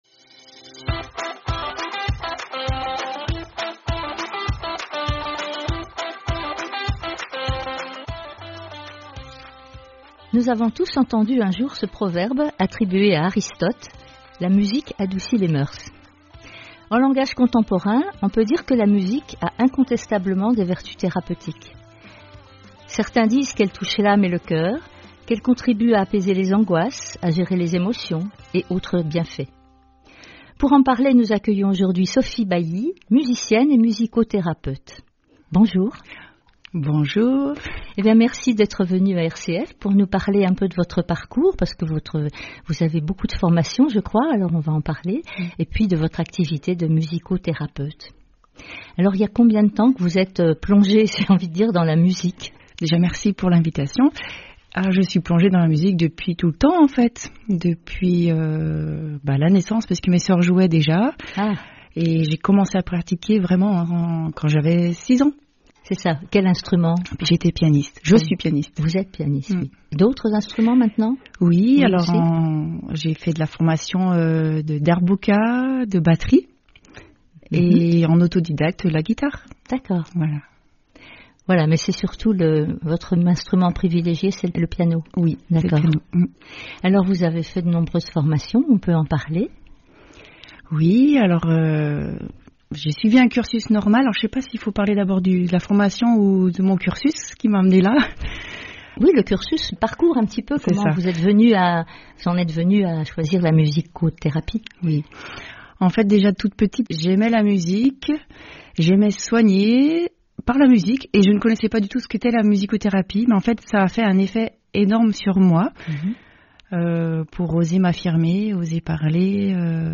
Retrouver l'interview au micro de RCF sur mon parcours, le public, ma passion et deux plages musicales en lien avec l'Iso Sonore